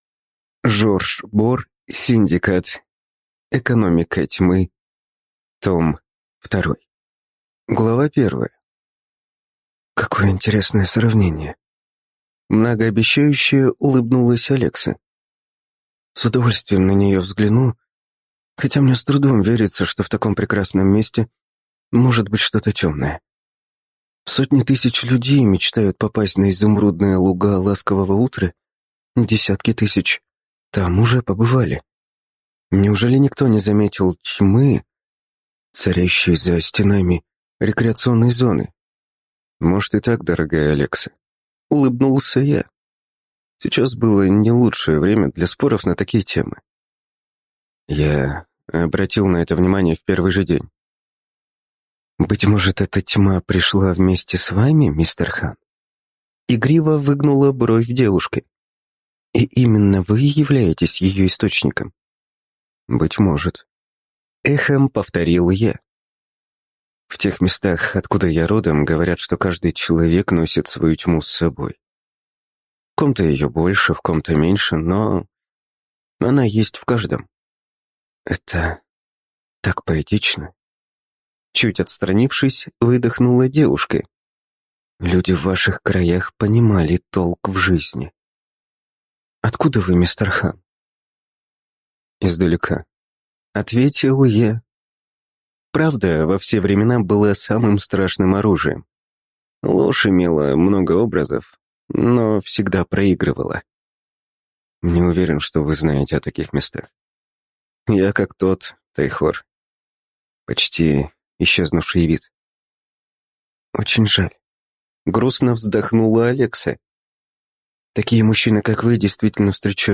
Аудиокнига Синдикат. Экономика Тьмы 2 | Библиотека аудиокниг